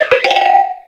Cri de Pitrouille Taille Ultra dans Pokémon X et Y.